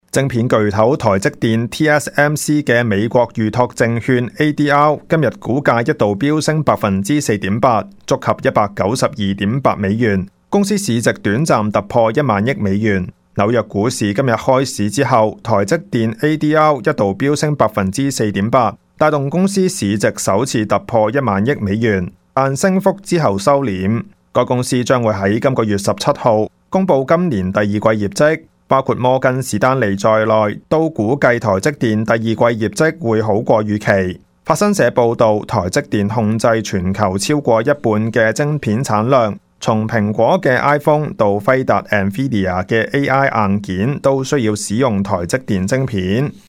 news_clip_19610.mp3